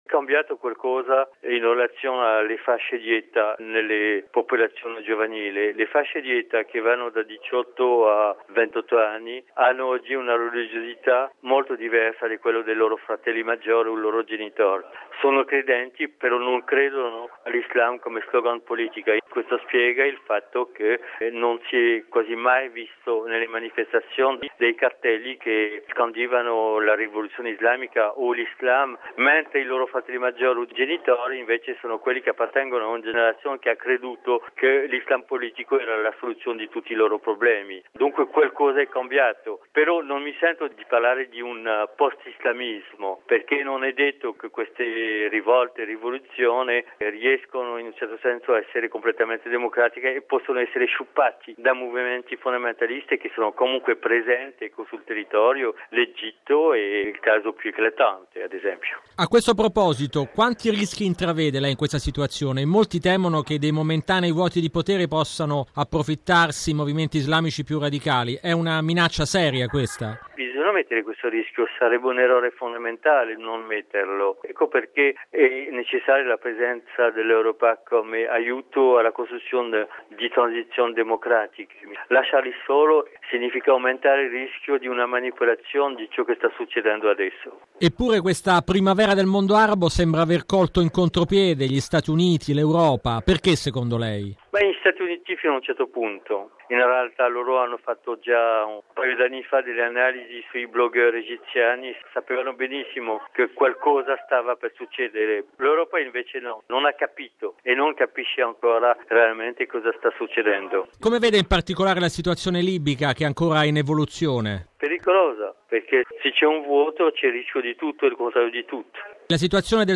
Bollettino del Radiogiornale della Radio Vaticana Anno LV no. 61